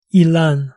"Ilan"